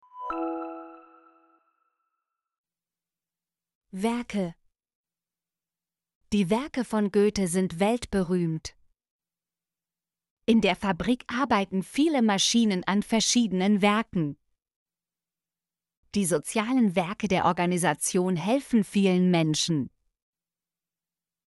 werke - Example Sentences & Pronunciation, German Frequency List